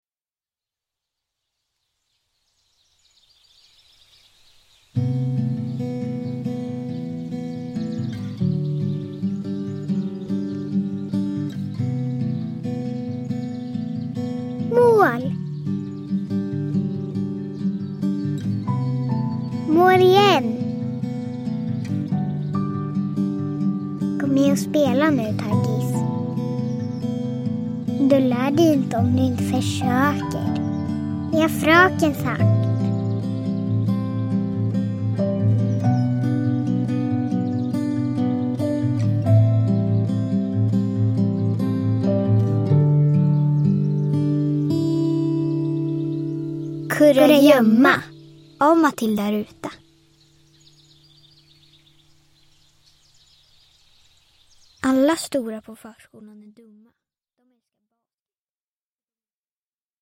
Kurragömma – Ljudbok